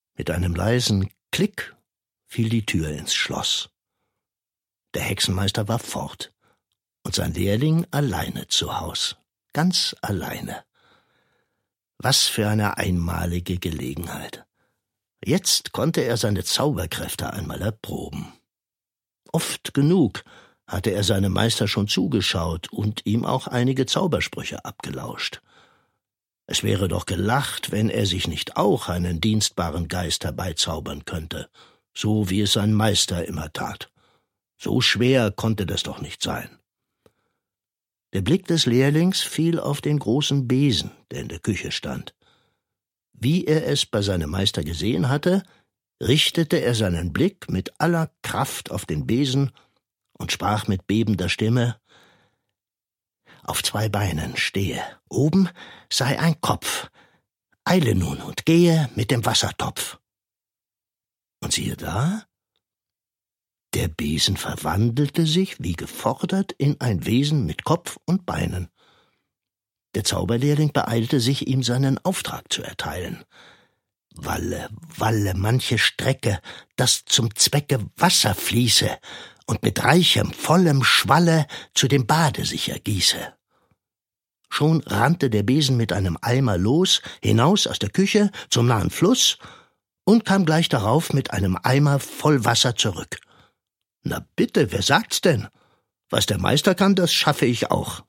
Balladen für Kinder erzählt
Schlagworte Balladen • Balladen; Kinder-/Jugendliteratur • Bürgschaft • Erlkönig • Gedichte • Hörbuch; Lesung für Kinder/Jugendliche • Zauberlehrling